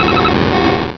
sound / direct_sound_samples / cries / wobbuffet.aif
-Replaced the Gen. 1 to 3 cries with BW2 rips.